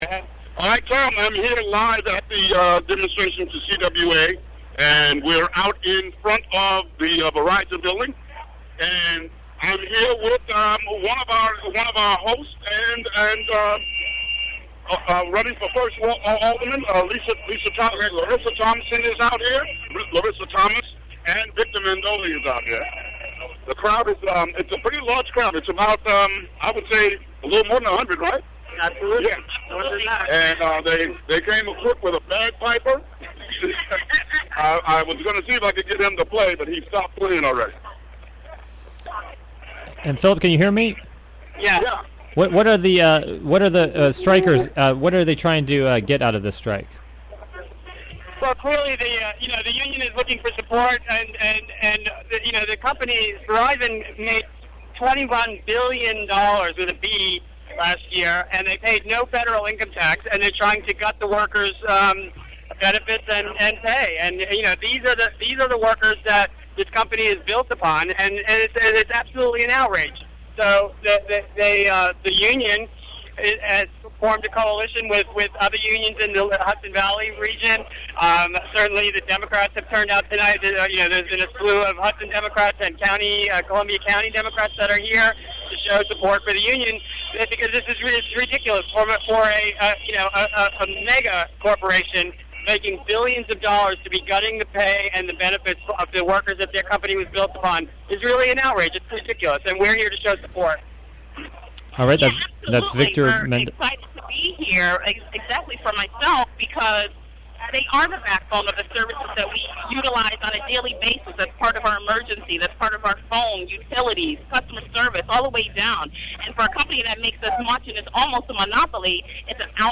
Live report from protest of striking workers at Verizon store in Greenport.